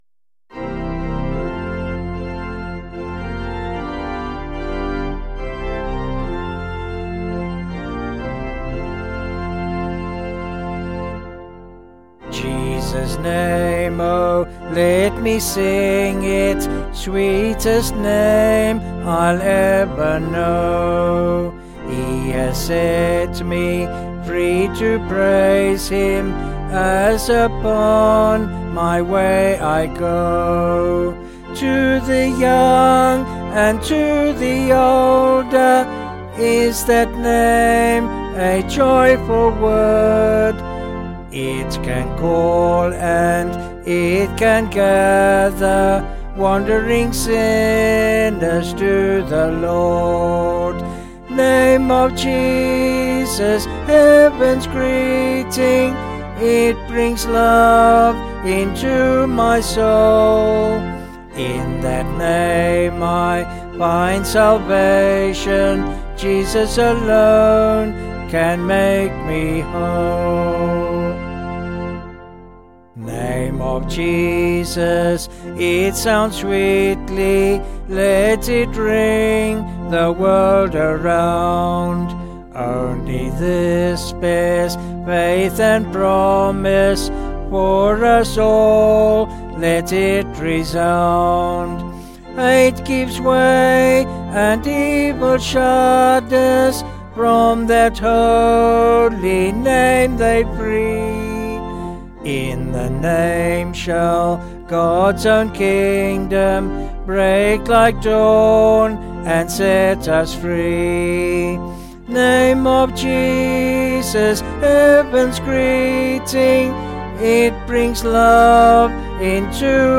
703.3kb Sung Lyrics